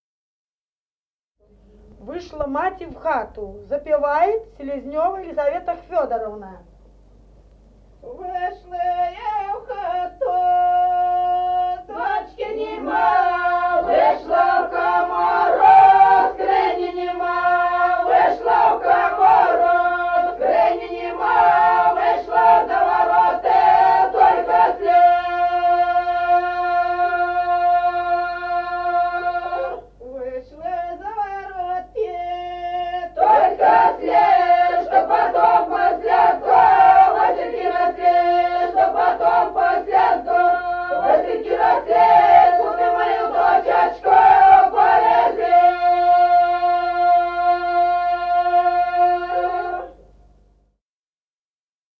Народные песни Стародубского района «Вышла я в хату», свадебная.
c. Остроглядово.